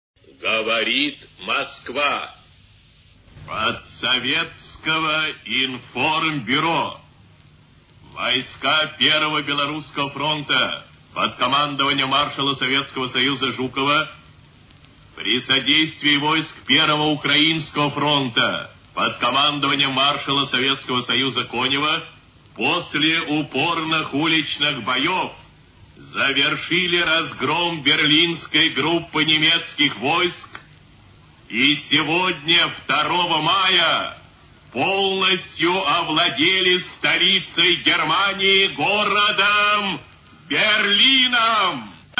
levitan_golos.mp3